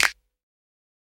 Snap (2).wav